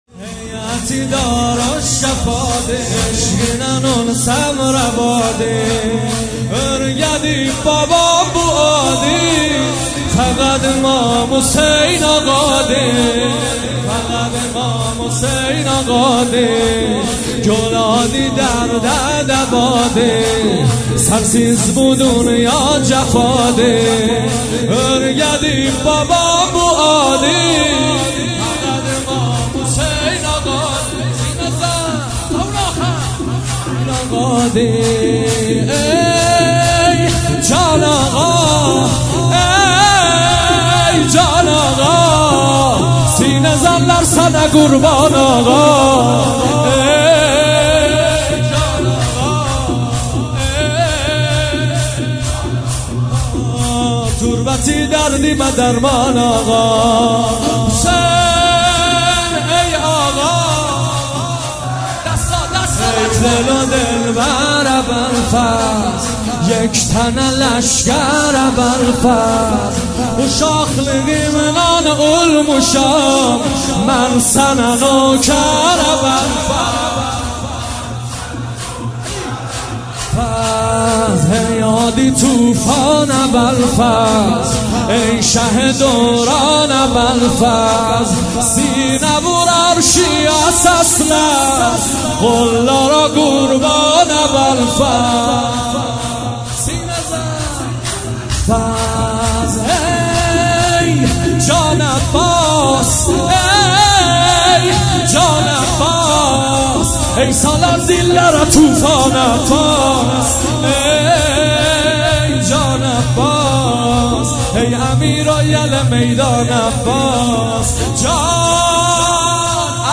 وفات حضرت معصومه (س) | مسجد حضرت معصومه(س) کرج